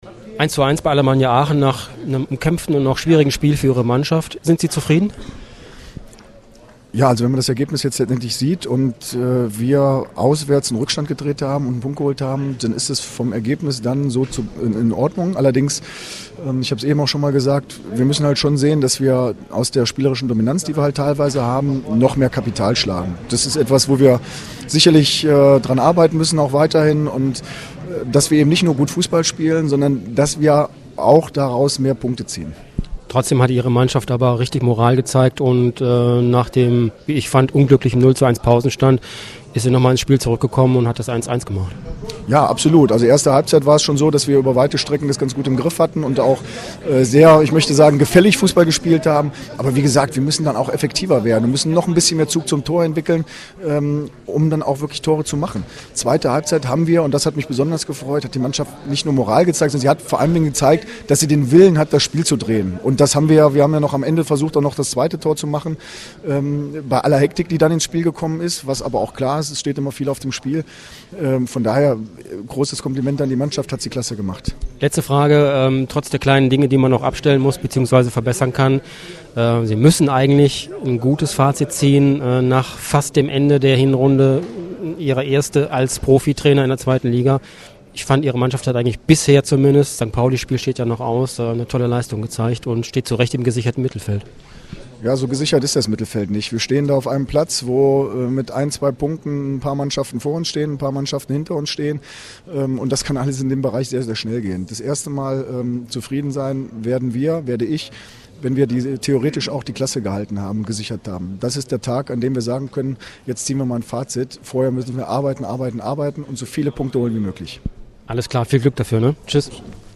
AUDIO-KOMMENTAR